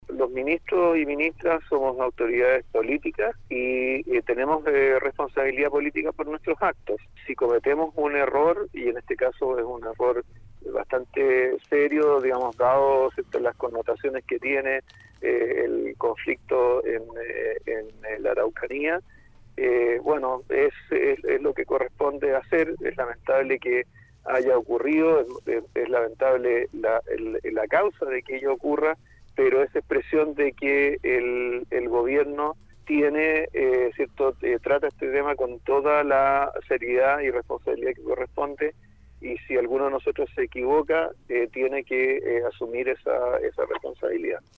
Al respecto, y en entrevista con Radio UdeC, Marcel sostuvo que «los ministros y ministras somos autoridades políticas y tenemos responsabilidad política por nuestros actos (…) Si alguno de nosotros se equivoca, tiene que asumir esa responsabilidad».
entrevista-mario-marcel-1.mp3